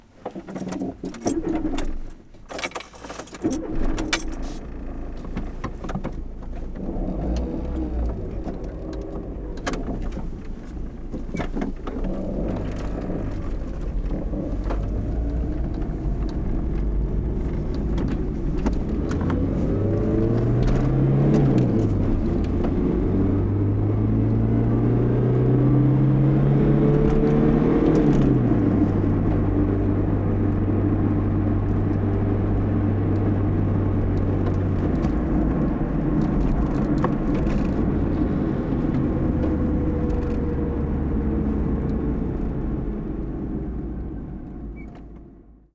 Arrancada y salida rápida de un Golf desde el interior del coche
coche
Sonidos: Transportes